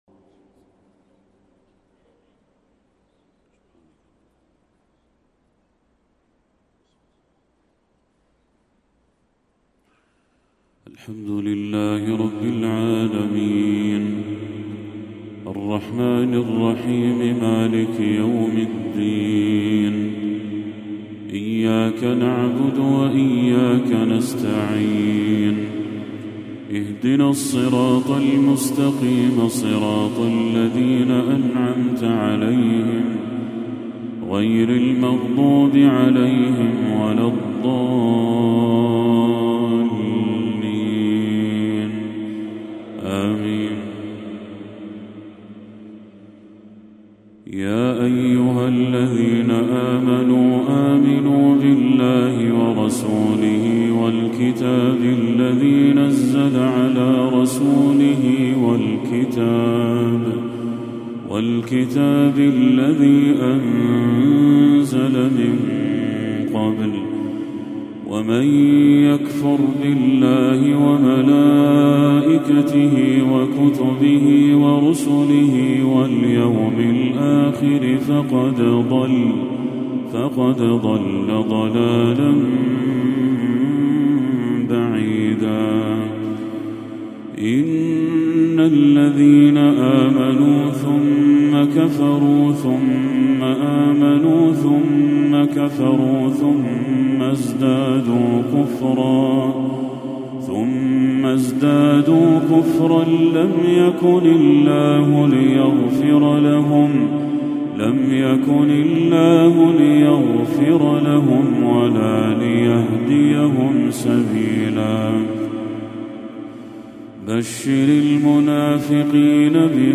تلاوة عذبة من سورة النساء للشيخ بدر التركي | عشاء 16 ربيع الأول 1446هـ > 1446هـ > تلاوات الشيخ بدر التركي > المزيد - تلاوات الحرمين